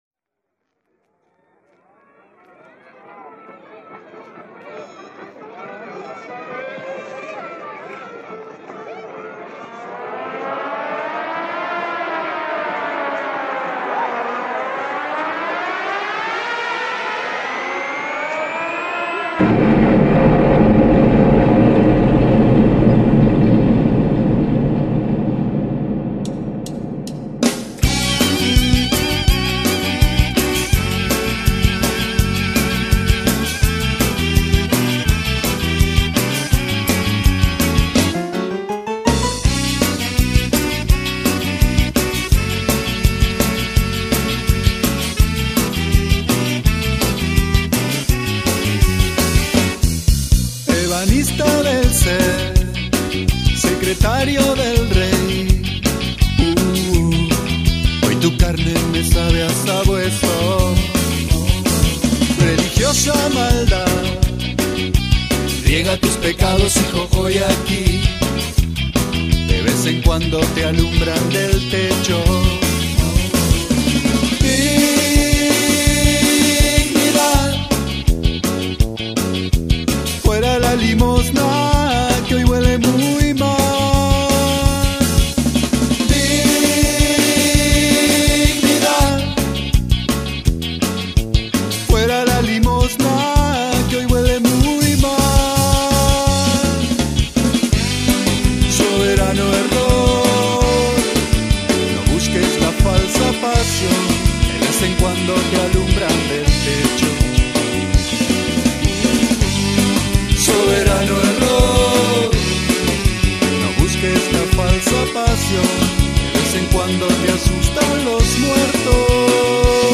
Demo grabado entre enero y abril del 2001
Voz, coros y percusión
Bajo, coros, teclados y percusión
Guitarra y coros
Primera guitarra, guitarra española y coros
Batería, teclado, percusión y coros
Saxos (tenor y alto)
saxo alto